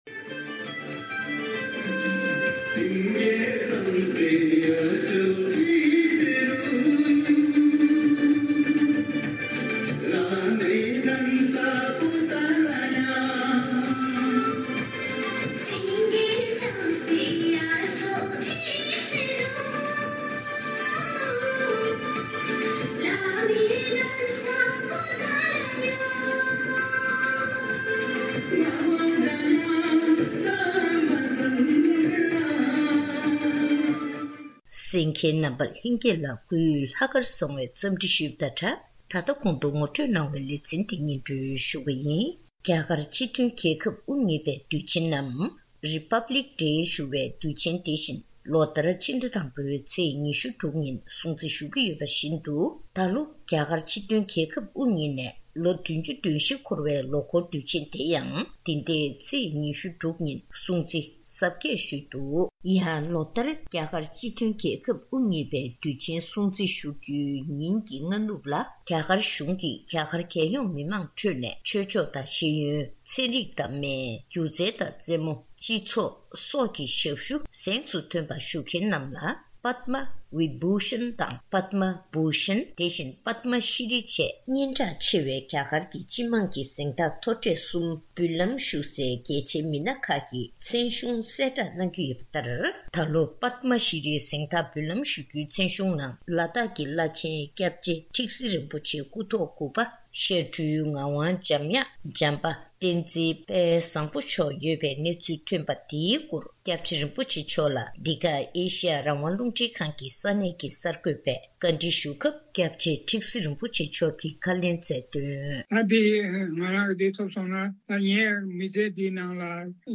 གནས་འདྲི་ཞུས་ནས་གནས་ཚུལ་ཕྱོགས་བསྒྲིགས་ཞུས་པ་ཞིག་གསན་རོགས་གནང།།